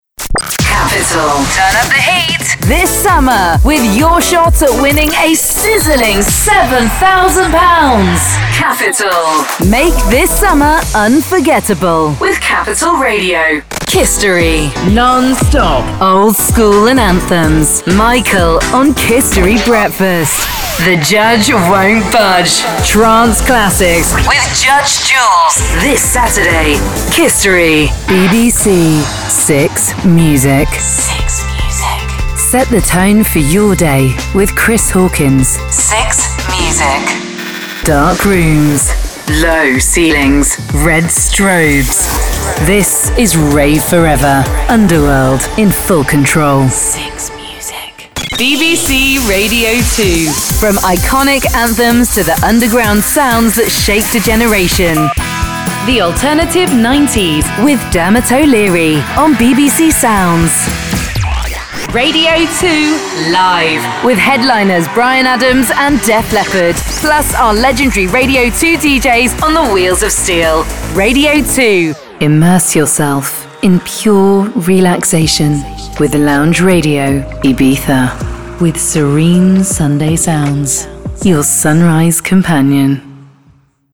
Radio Imaging Showreel
Female
Neutral British
Husky (light)
Friendly